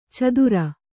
♪ cadura